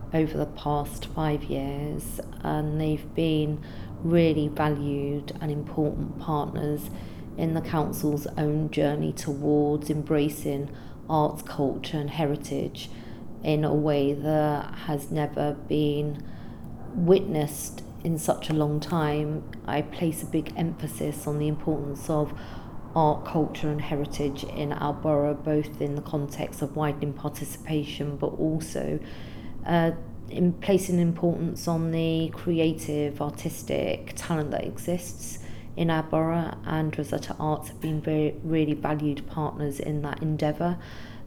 Rokshana Fiaz – Oral History